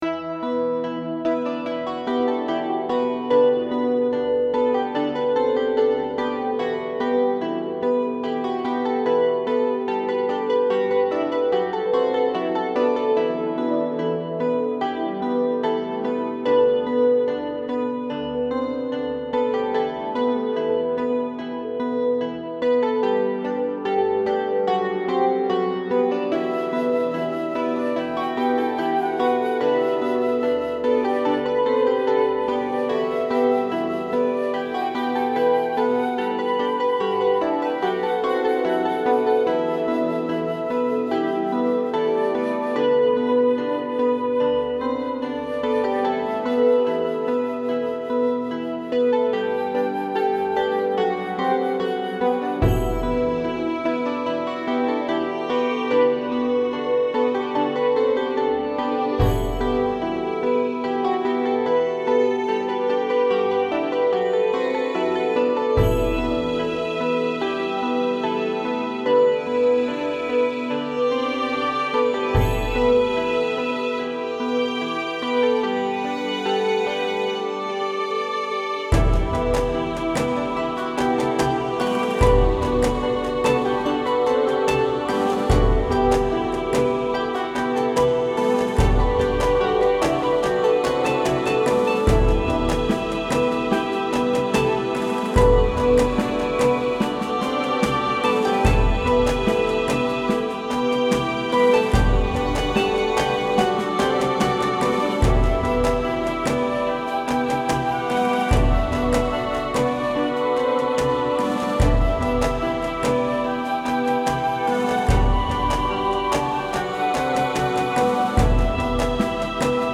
This tune is always great for an RPG (no suprise there). I kind of feel like this would be a great background to exploring a forest of some kind getting closer and closer to a boss perhaps..I dunno I just tinker with instruments and hope to get lucky with a melody..Anyways, as always Cheers!